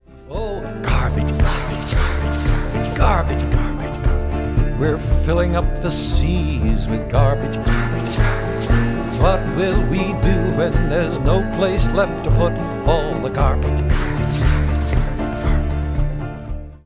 voice, banjo
banjo
bass
drums
chorus